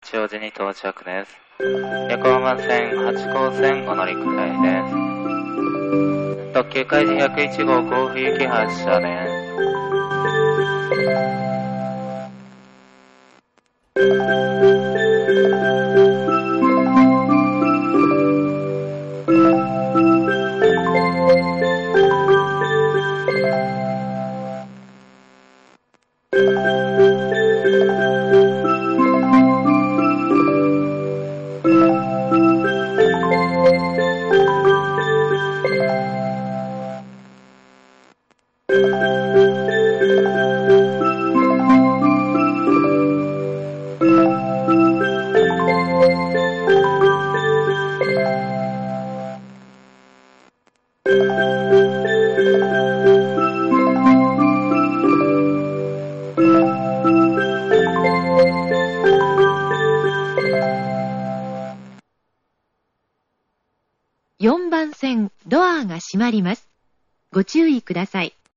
発車メロディ 曲名は『夕焼けこやけD』です。4.9コーラスです。
即切り
常時立ち番が居るため、駅員放送がほとんどの確率で被ります。
hachioji-4_melody.mp3